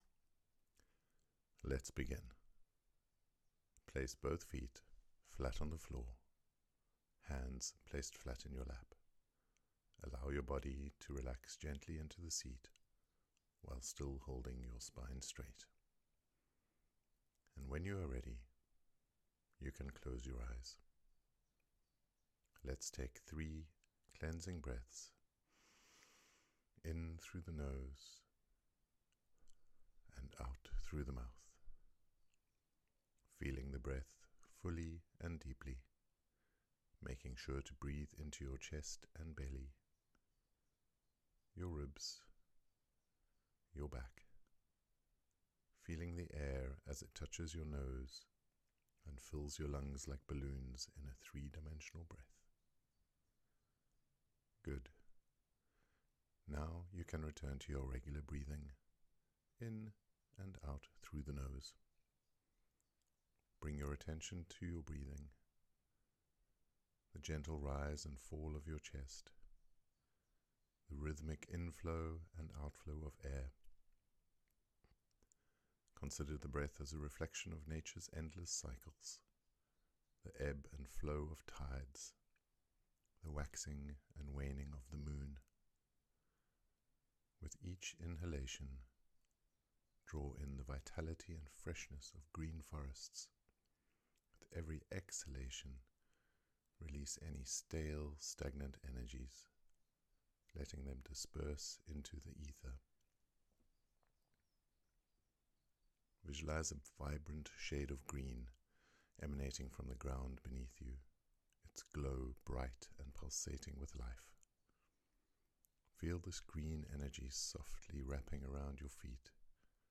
feRn Believer (Green) Meditation